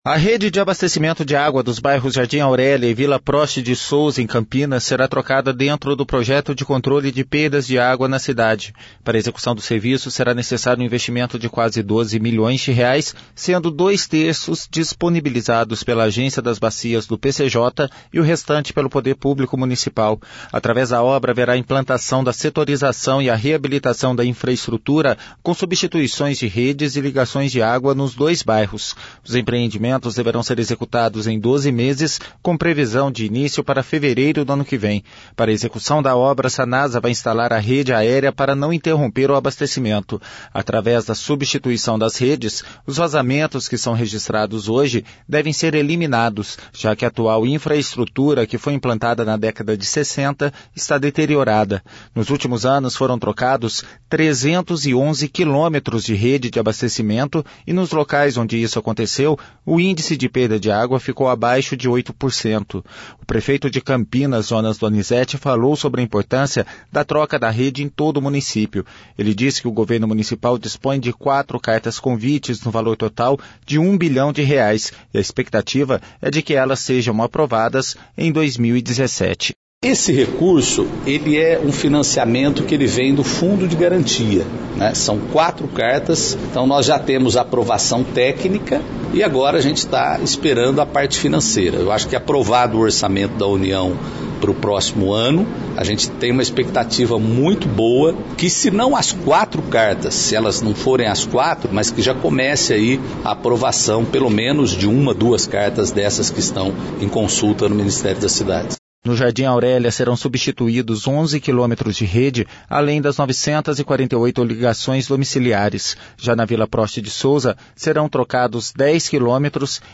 O prefeito de Campinas, Jonas Donizette, falou sobre a importância da troca da rede em todo o município.